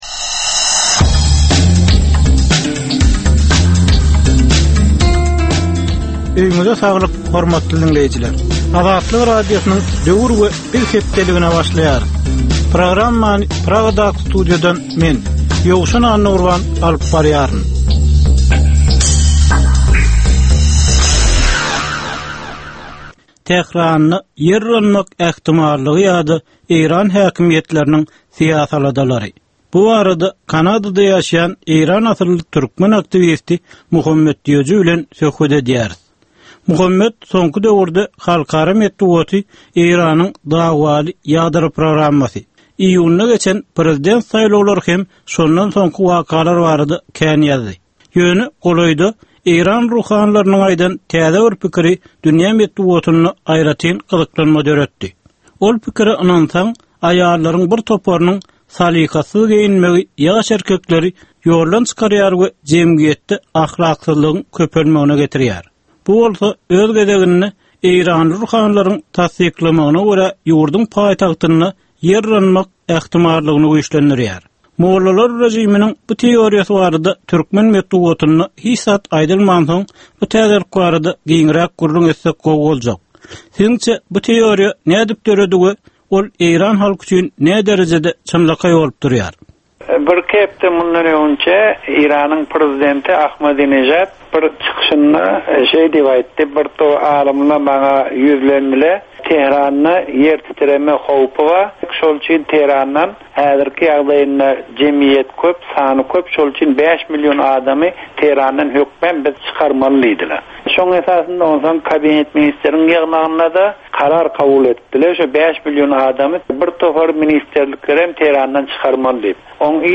Türkmen jemgyýetindäki döwrüň meseleleri. Döwrüň anyk bir meselesi barada ýörite syn-gepleşik. Bu gepleşikde diňleýjiler, synçylar we bilermenler döwrüň anyk bir meselesi barada pikir öwürýärler, öz garaýyşlaryny we tekliplerini orta atýarlar.